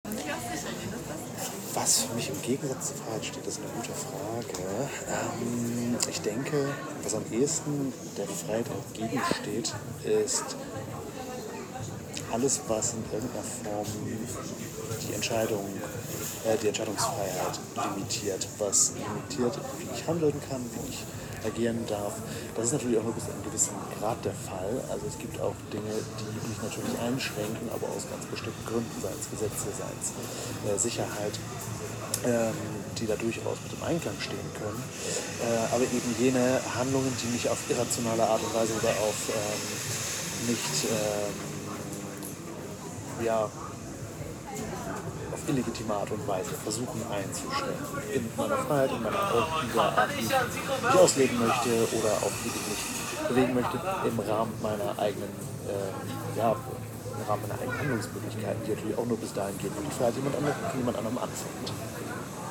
Standort der Erzählbox:
Bürgerfest Schwerin im Rahmen des Projektes